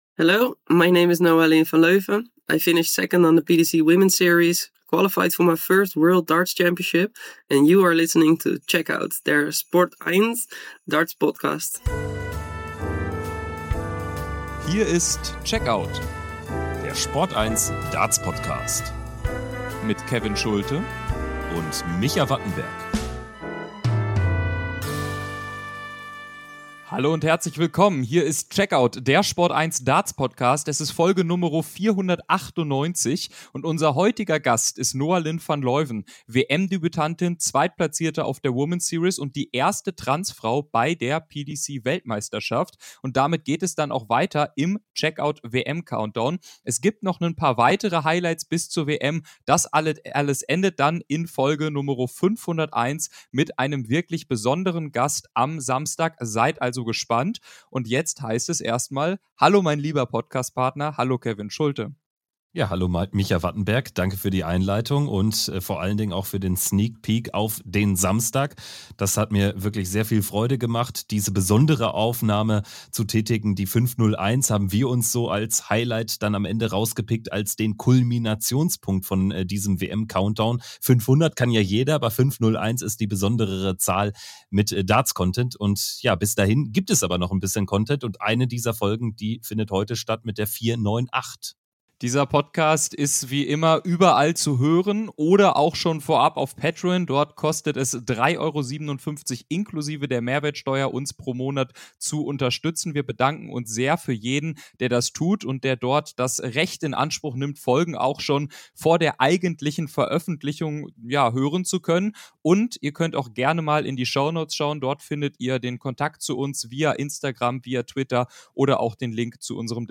Sie hat schon mit ihrer Qualifikation für den Ally Pally Darts-Geschichte geschrieben: Als erste Transfrau in der Geschichte tritt die Niederländerin Noa-Lynn van Leuven bei der Weltmeisterschaft der PDC an. Im exklusiven Podcast-Interview schildert sie ihren Weg zur WM, berichtet von all den Höhen und Tiefen und lässt dabei nichts aus: Selbstmordgedanken in ihrer Jugend.